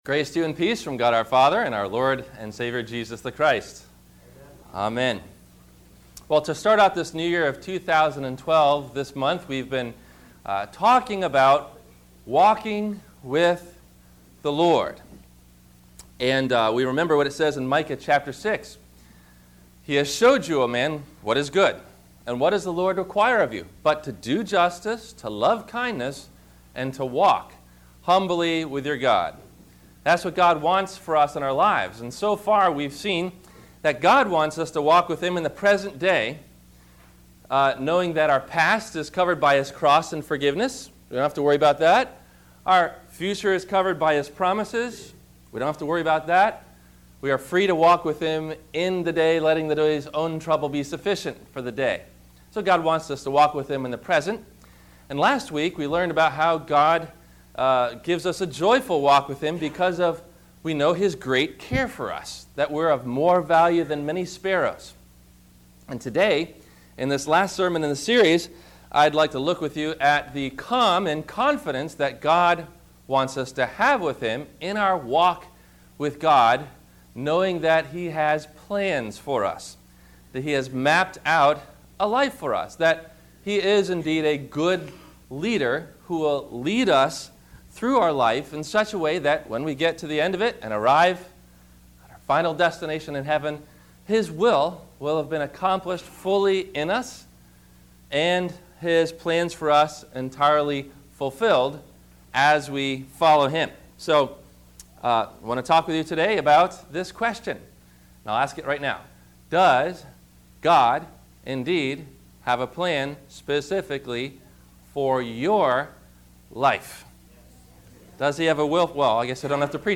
God’s Plan For Your Life - Sermon - January 29 2012 - Christ Lutheran Cape Canaveral